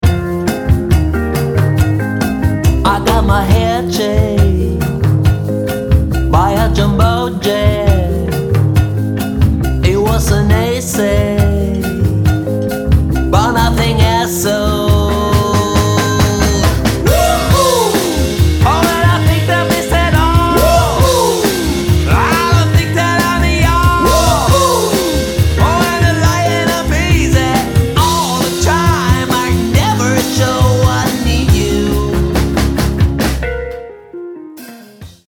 jazz can dance